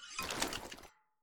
heavy_holster.ogg